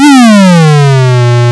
doppler.wav